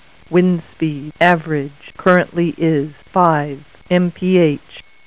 Weather Word connects to a standard telephone line, can run on batteries, and answers the phone with a pleasing female voice
Typical Telephone Answer: